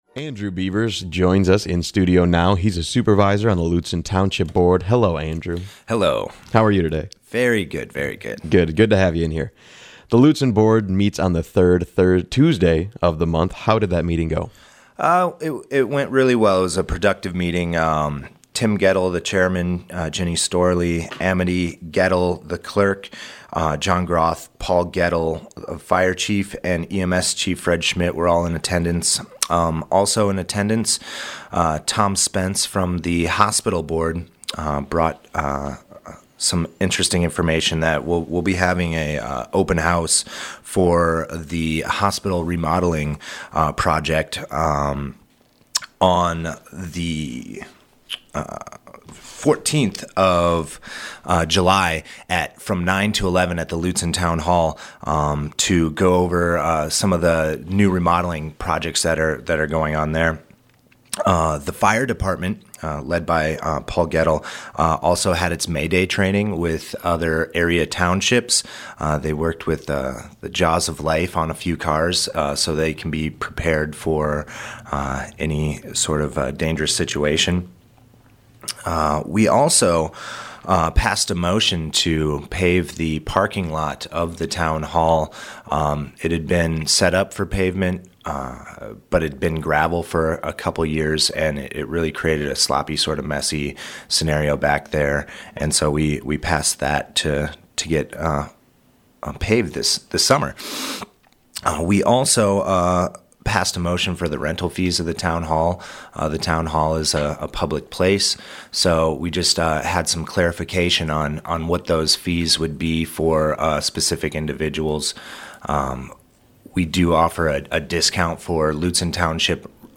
WTIP talked to Andrew Beavers, supervisor of the Lutsen Township Board, about their most recent meeting.